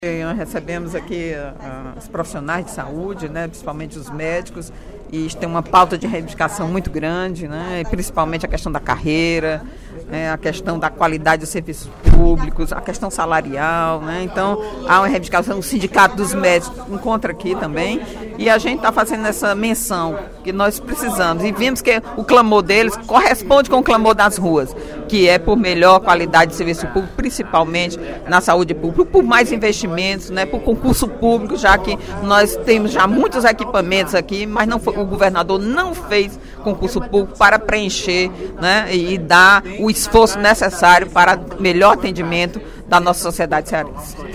Durante o primeiro expediente da sessão plenária desta quarta-feira (03/07), a deputada Eliane Novais (PSB) repercutiu as reivindicações por melhores condições de trabalho da classe médica cearense.